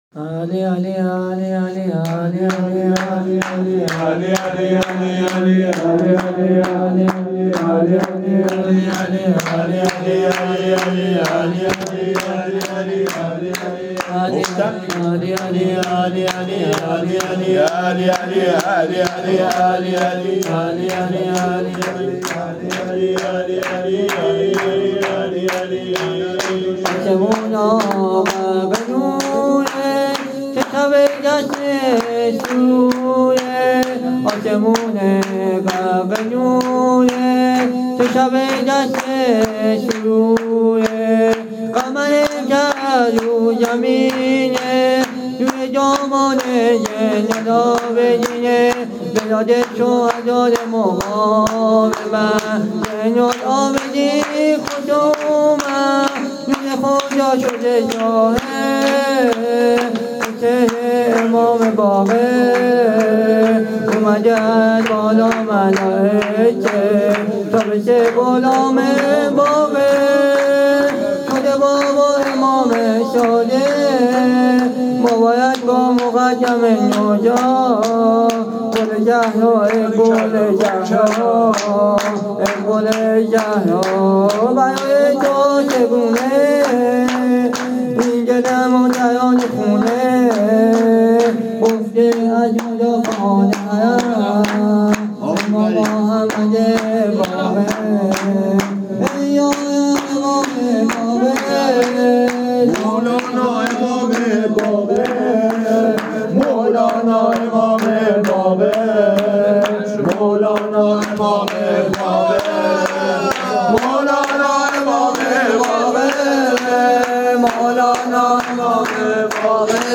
مداح اهل بیت
مولودی خوانی
هیئت عشاق العباس تهران